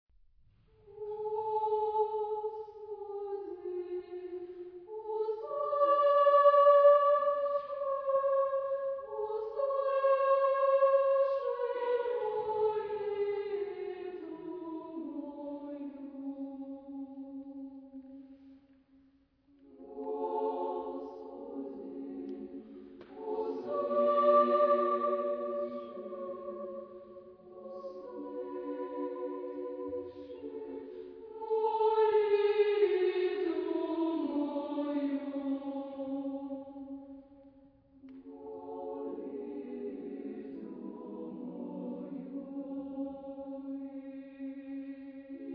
Genre-Style-Forme : Motet ; Sacré
Caractère de la pièce : andante
Type de choeur : SSAA OU TTBB  (4 voix égales )
Tonalité : fa majeur